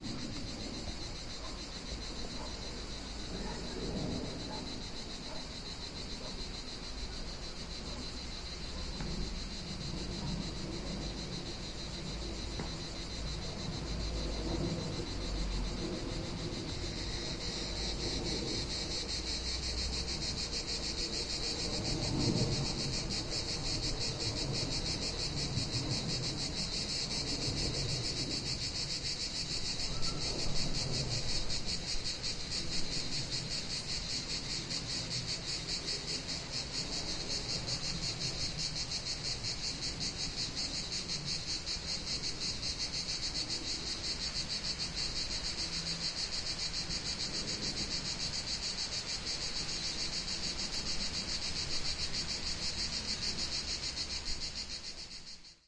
在炎热的夏日，当您沿着小径漫步时，可以在灌木丛中听到蝉声。远远低于，不安的海洋的声音。
Tag: 昆虫 性质 现场记录